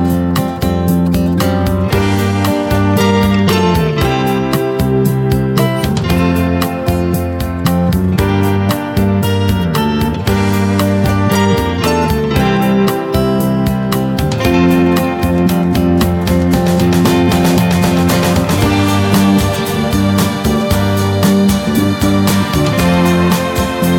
Minus Lead Guitar Pop (1970s) 2:58 Buy £1.50